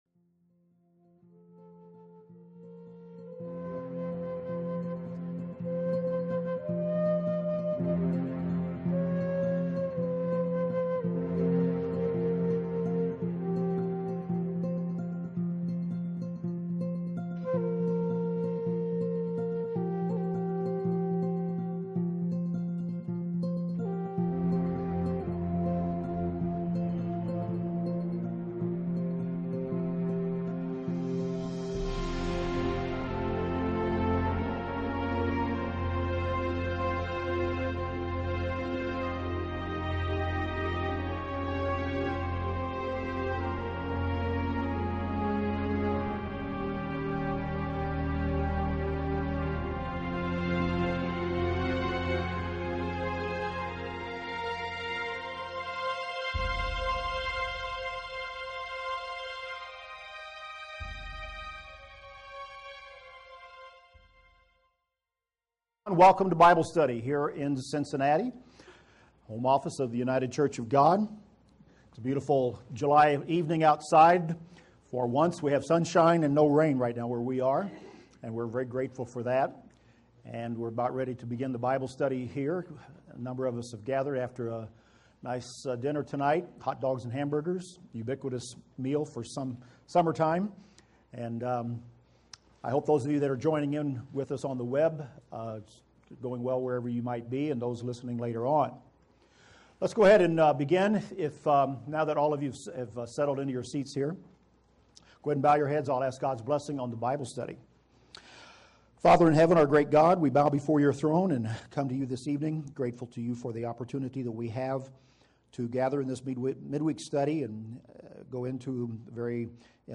This is the fourth part in the Bible study series: The Churches of Revelation.